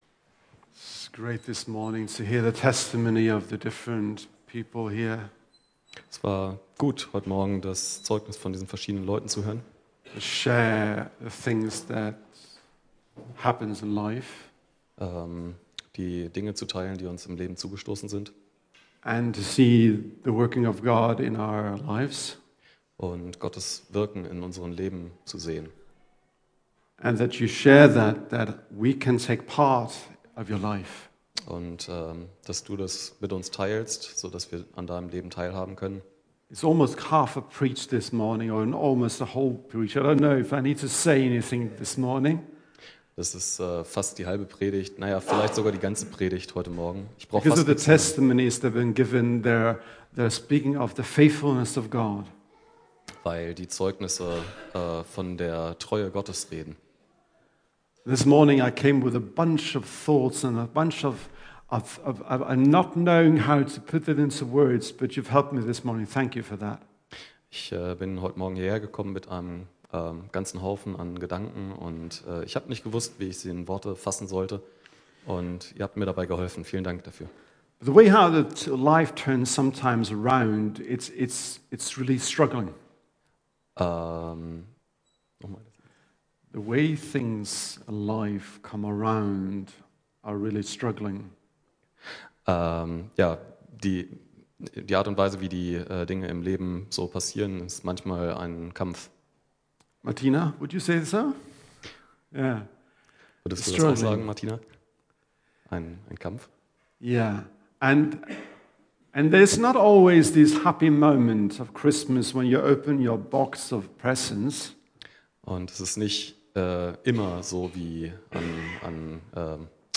Kirche am Ostbahnhof, Am Ostbahnhof 1, 38678 Clausthal-Zellerfeld, Mitglied im Bund Freikirchlicher Pfingstgemeinden KdöR
Predigt